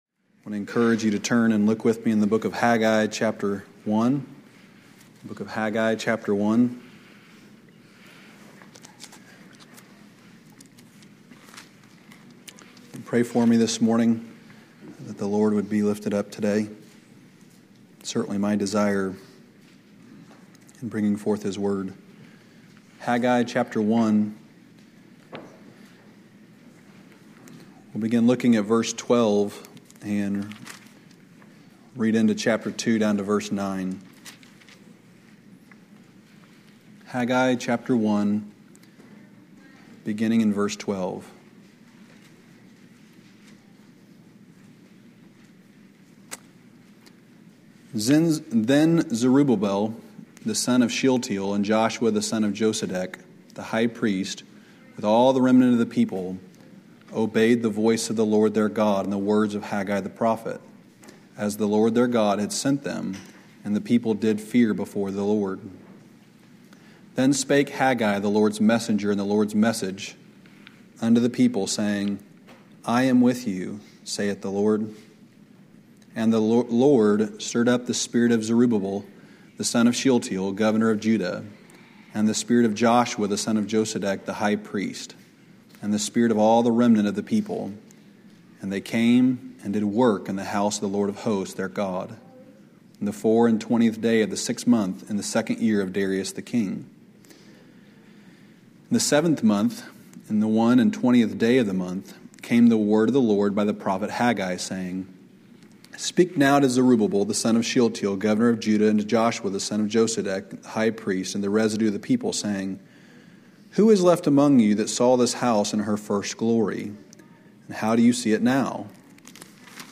From Series: "Sunday Morning Messages"